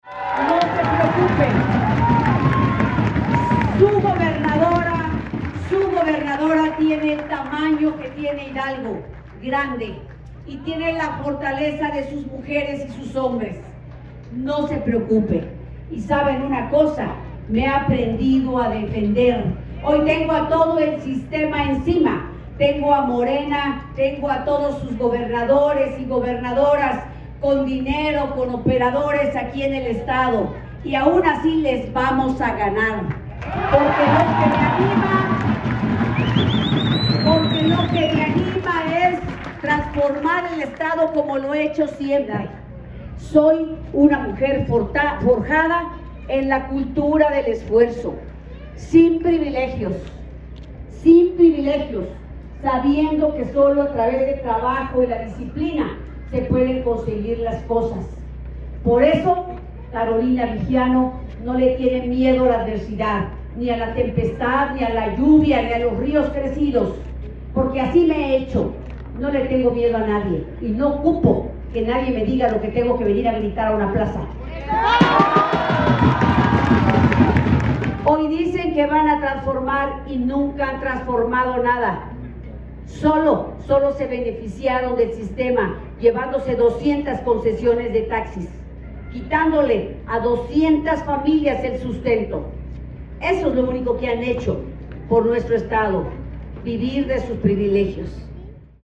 Reunida con las estructuras panistas, priistas y perredistas en la plaza principal de Santiago de Anaya, la candidata aliancista aseguró no temerle a la adversidad pues durante su vida profesional ha enfrentado varios obstáculos y ha salido avante.